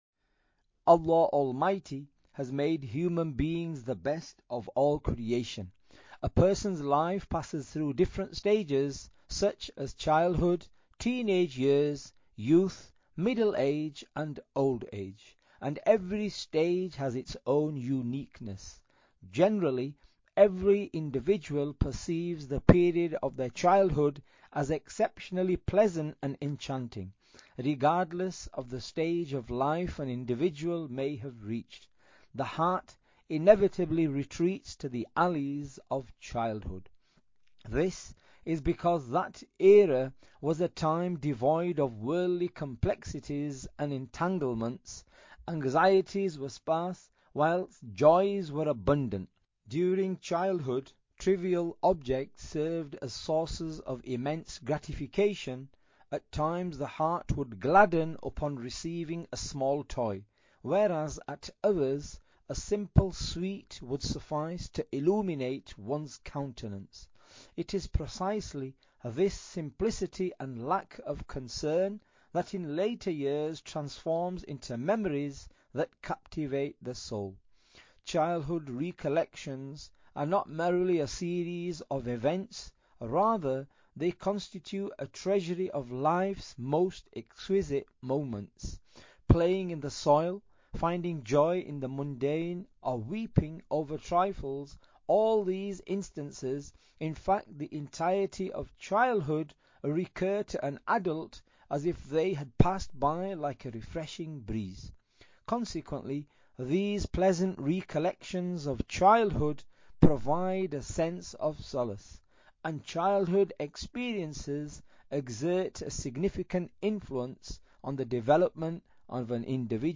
Audiobok - The Childhood of The Amir of Ahl al Sunnah-Part 01 (English)